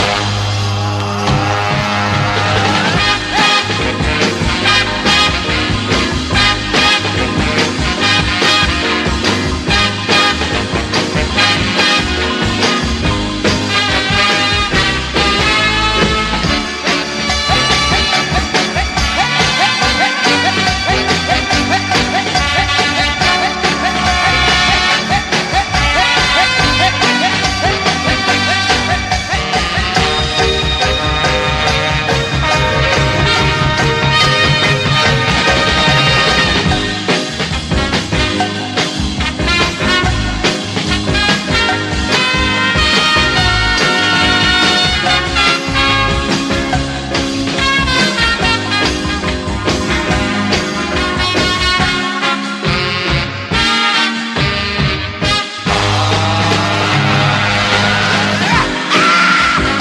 JAZZ / MAIN STREAM / MODAL